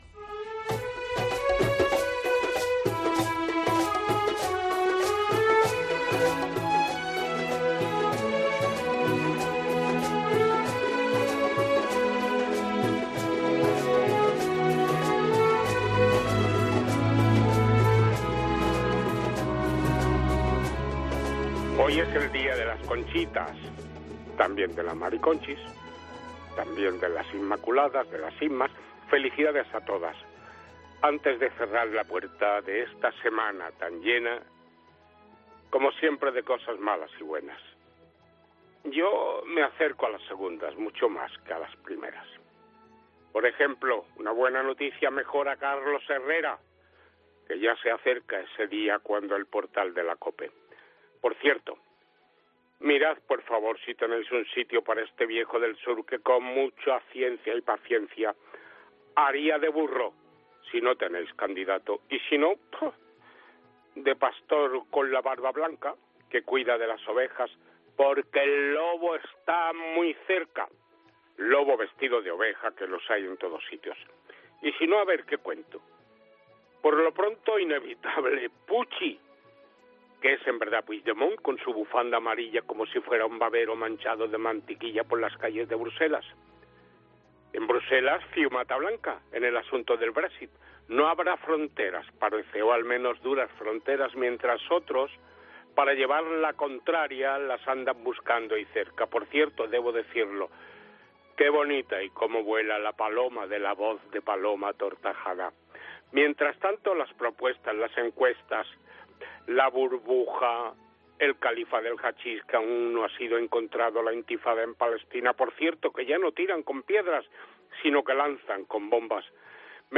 Las quejas de los exconsejeros catalanes excarcelados por la comida en la cárcel de Estremera,"¿qué se cree Turull, que en el 'trull' hay un restaurante de cinco estrellas?", el frío, el día de las Conchas, Conchitas e Inmas, son algunos de los temas que pone en verso el maestro de periodistas.